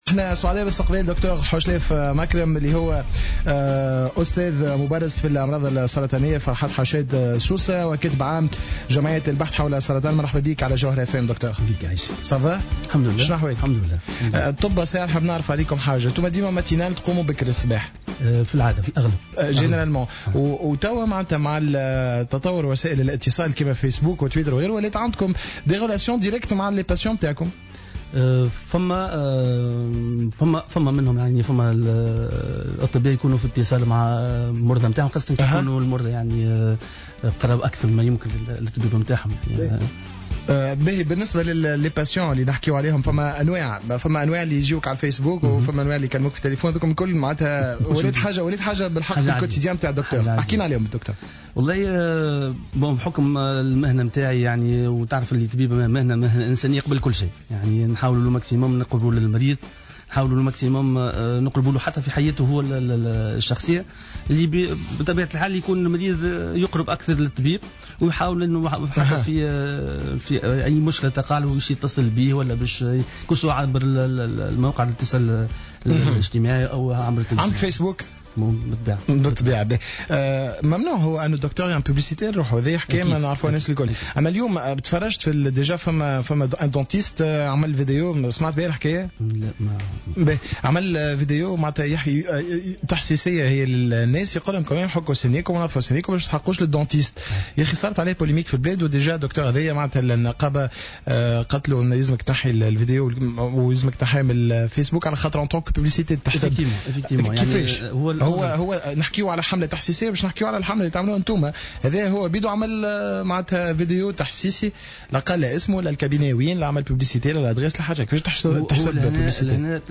وقال في مداخلة على موجات "الجوهرة أف أم" في برنامج "هابي دايز" اليوم الخميس إن جمعيته ستنظم يوما تحسيسيا حول هذا المرض الخطير على شاطئ بوجعفر بمناسبة موسم العطلة والاصطياف وتواجد المصطافين.